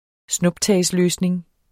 Udtale [ -ˌløːsneŋ ]